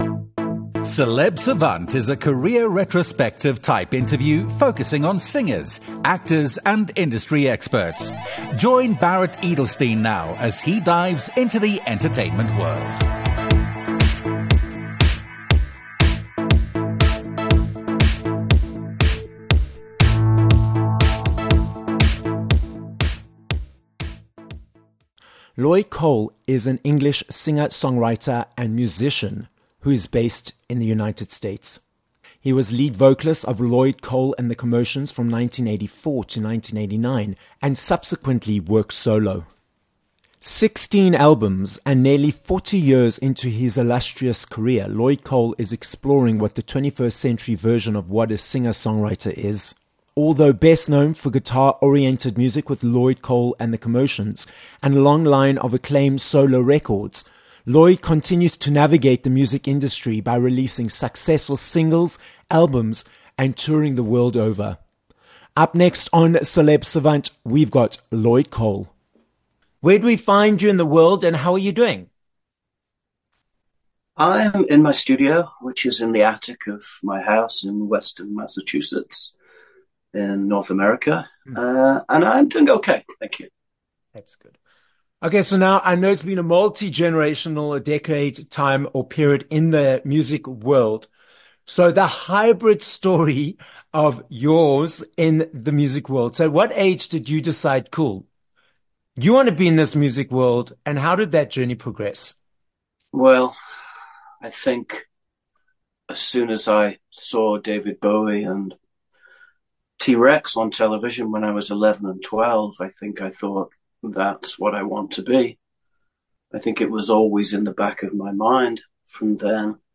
14 Apr Interview with Lloyd Cole
With over 40 years in the industry, British singer, songwriter and musician, Lloyd Cole joins us from the USA where he is currently based. Lloyd tells us about the years that he was part of the band, Lloyd Cole and the Commotions, we hear what motivates him to keep creating music, and more about his latest releases.